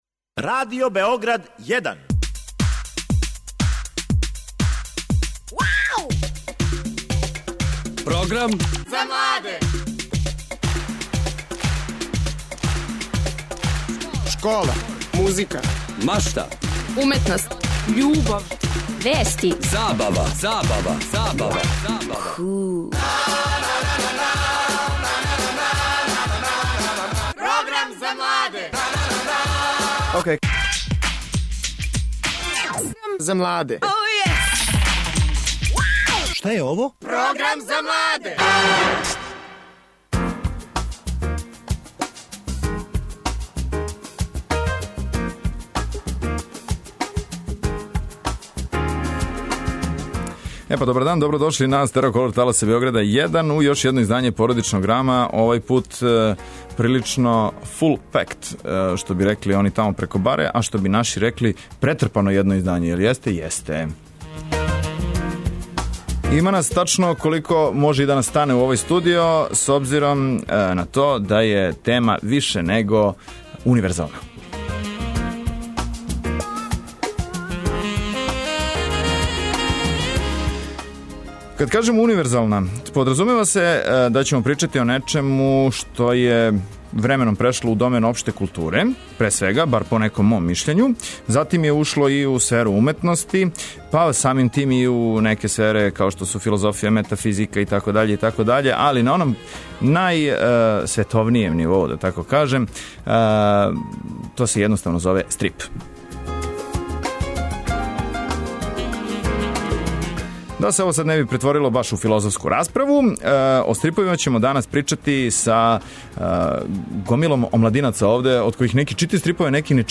разговара са малишанима и њиховим родитељима